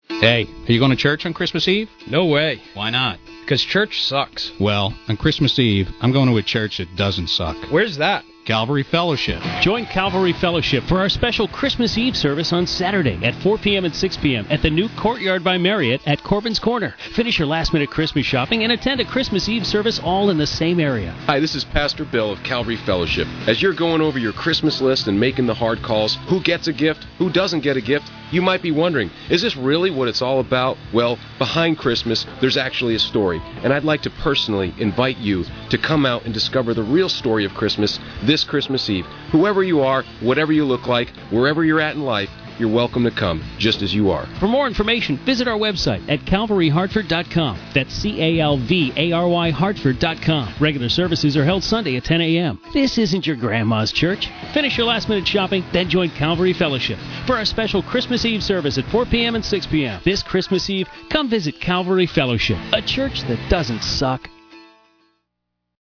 Radio commercial:
01 WCCC Christmas Spot.mp3